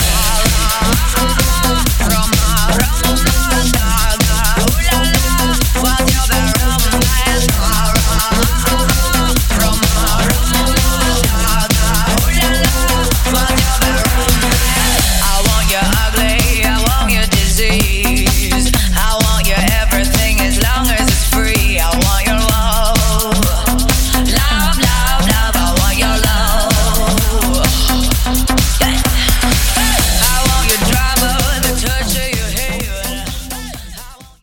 Genre: RE-DRUM
Clean BPM: 130 Time